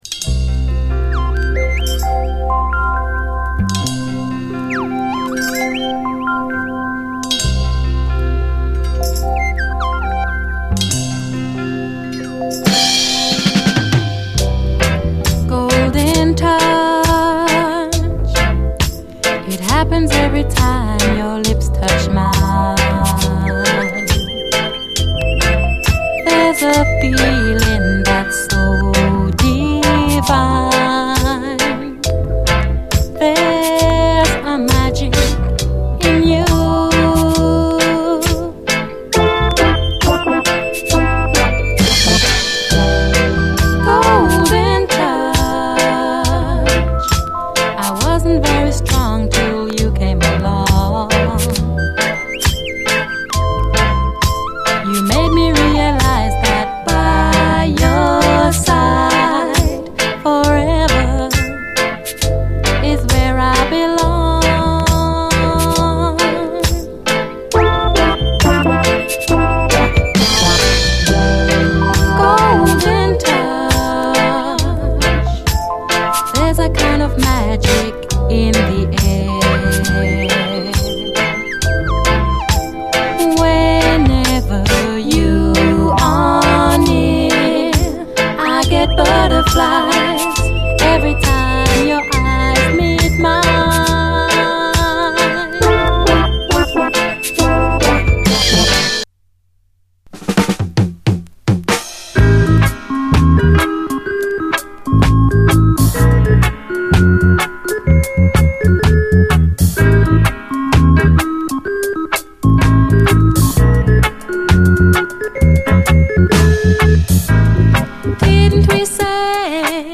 幸福感で満たすブラスのミディアム・ラテン・ダンサー